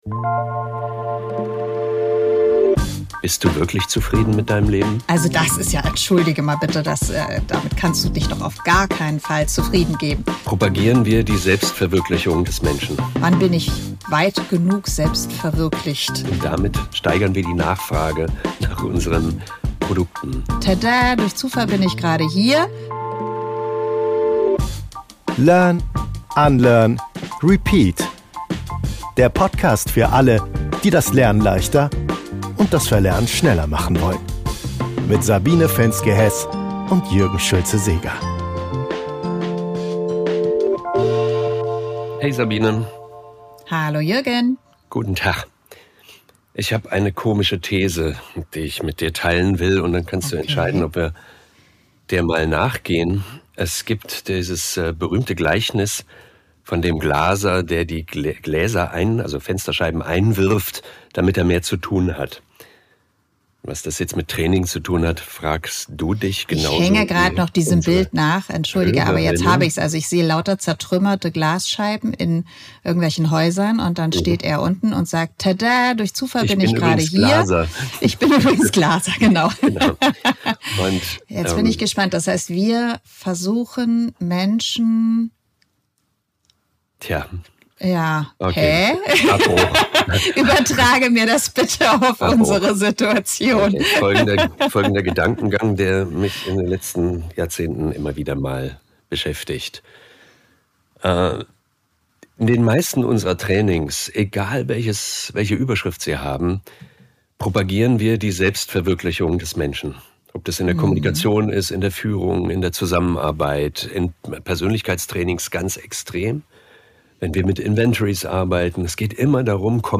Sie stellen sich die Frage: Unterstützen wir Menschen – oder schüren wir erst die Unzufriedenheit, die wir dann professionell „beheben“? Ein ehrliches Gespräch über Verantwortung, psychologische Sicherheit, Selbstannahme und den Mut zum Müßiggang.